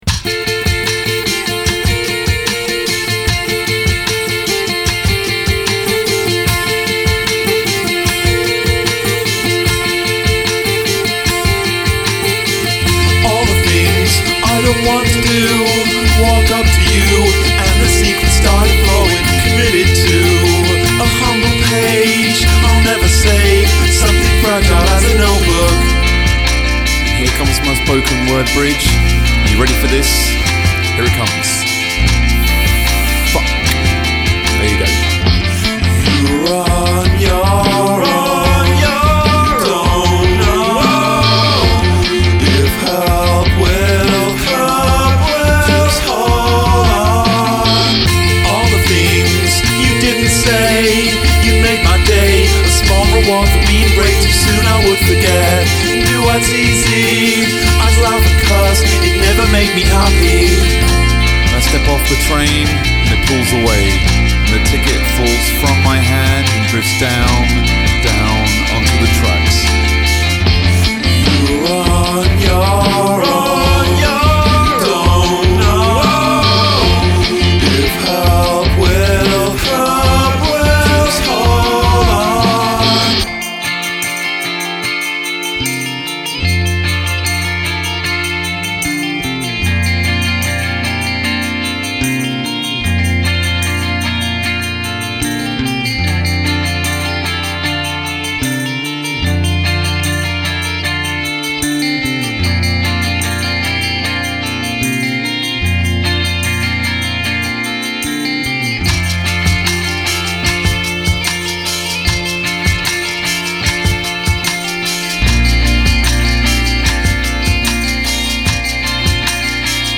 Must include a spoken word bridge